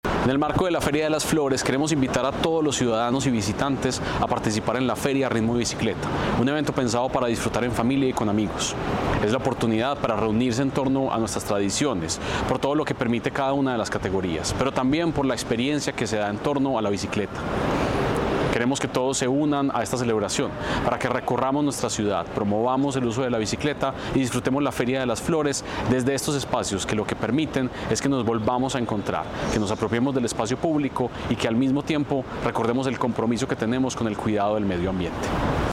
Palabras de Santiago Silva, secretario de Cultura Ciudadana